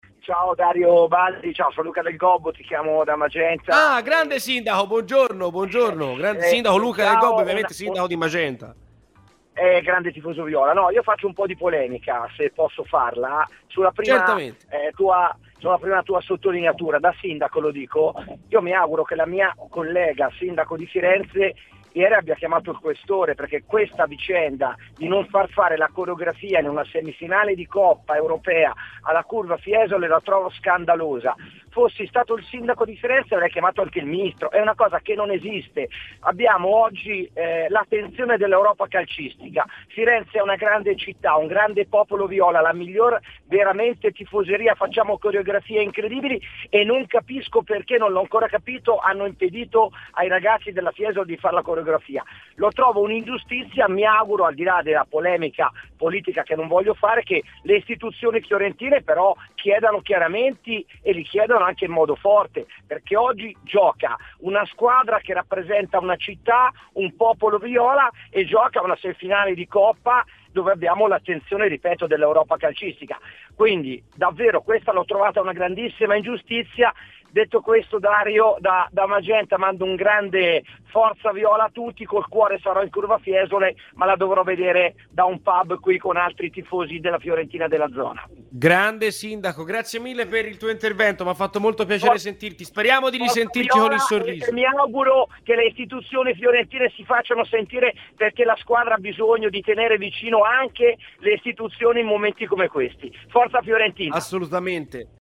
Luca Del Gobbo, sindaco di Magenta nonché grande tifoso della Fiorentina, intervenuto a Radio FirenzeViola durante "C'è polemica", ha preso posizione sulla coreografia negata dalla Questura di Firenze alla Curva Fiesole, che si è vista così annullare tutto quel che aveva preparato per la gara di stasera al Franchi contro il Betis: "Da sindaco, mi auguro che la mia collega Sara Funaro abbia chiamato il questore, perché decidere di non far fare la coreografia in una semifinale europea alla Curva Fiesole è una cosa scandalosa.